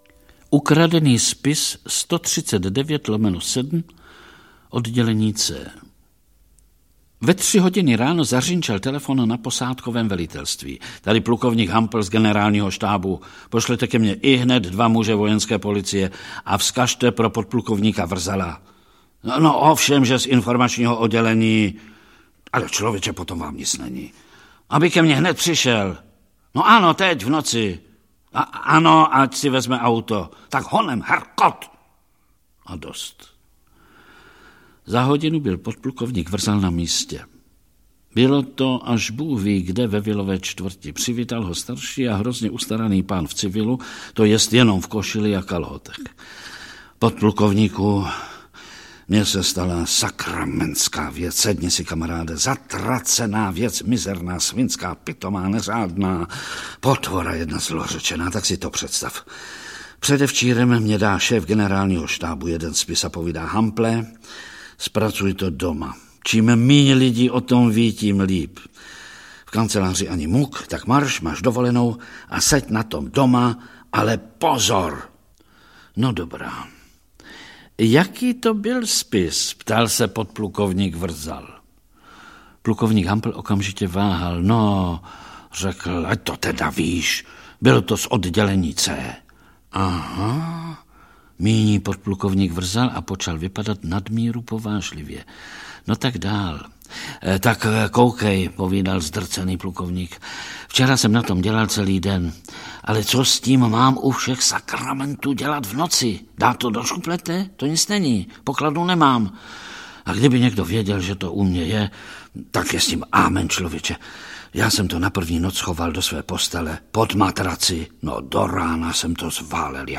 Audio kniha
• InterpretVlastimil Brodský, Ota Sklenčka, Miroslav Moravec